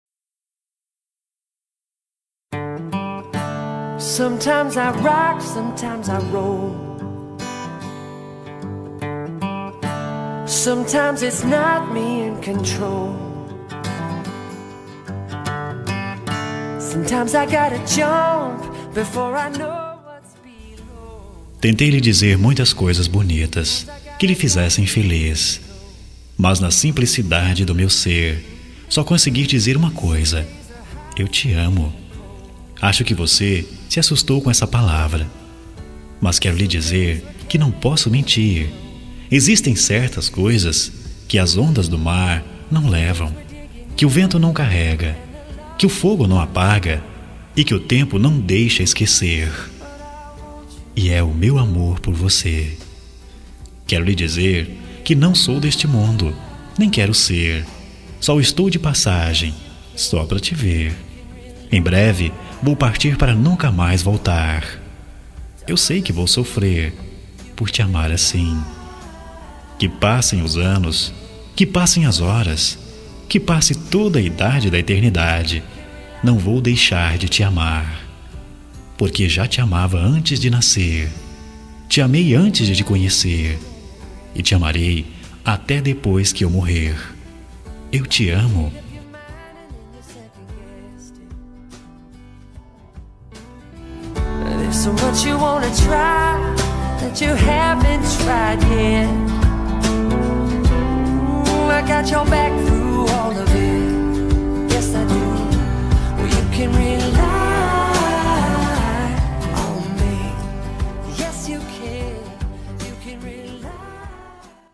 Voz Masculino